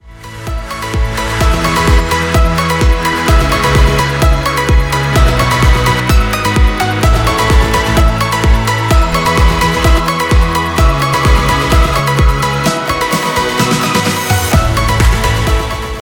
私が作ったEDMの中でエフェクトを一つずつ使用してみましたので、ここで試しに気になるものがあるか聞いてみてください。
これらは効果がわかりやすいように大げさに使用しているので曲中ではこんな使い方はしませんが。
(個別の楽器に使うとか、一部のトラックに使う方が良さそうですが曲全体に適用しちゃってます)
※音量注意
Delay↓
delay.mp3